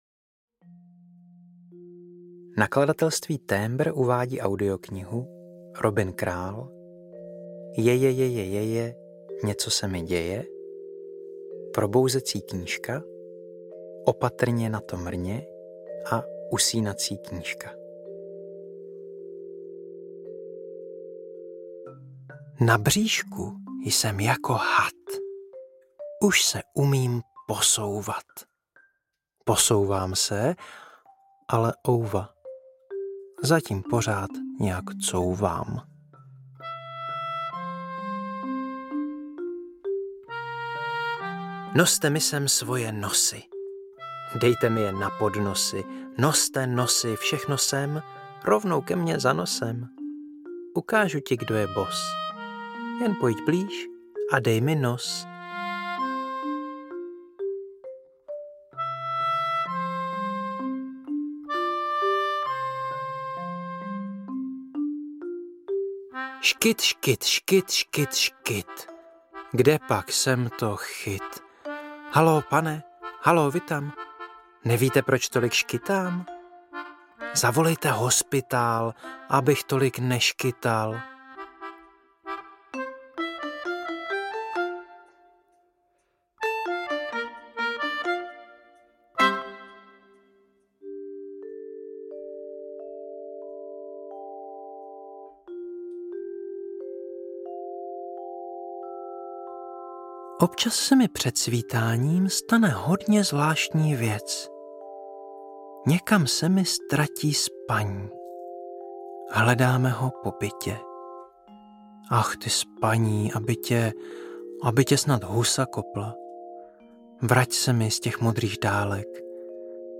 Ukázka z knihy
Natočeno ve studiu ArtDOG